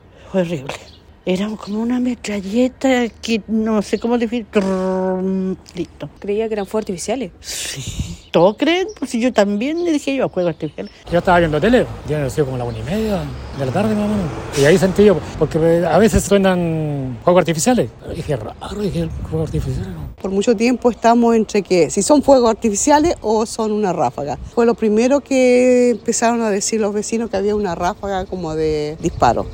Los vecinos pensaron que eran fuegos artificiales, pero resultó ser una ráfaga de disparos. “Fue horrible, era como una metralleta”, describió una de las residentes.